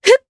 Talisha-Vox_Attack1_Jp.wav